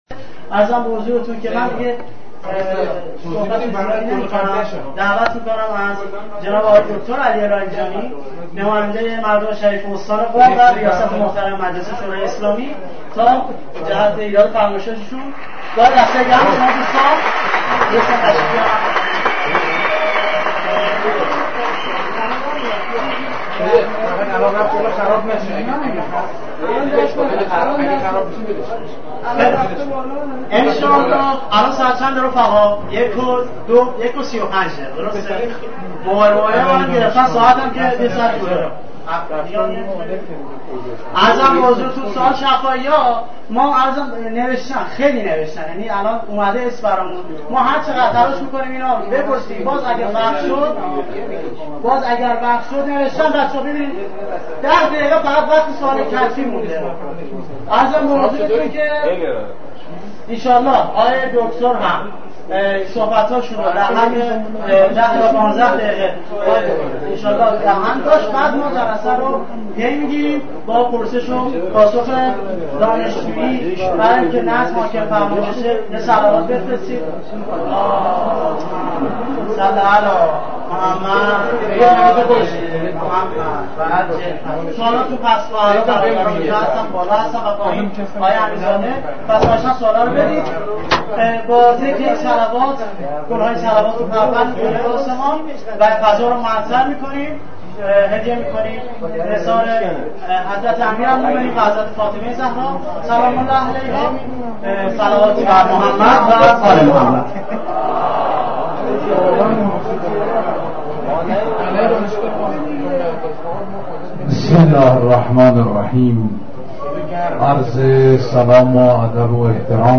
عصر دیروز دکتر علی لاریجانی، ریاست مجلس شورای اسلامی به دانشگاه قم رفت تا به سئوالات صدها دانشجوی مشتاق این دانشگاه پاسخ گوید. این مراسم نیز همچون همه جلسات دانشجویی پر از حاشیه بود.